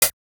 Index of dough-samples/ tidal-drum-machines/ machines/ LinnLM1/ linnlm1-hh/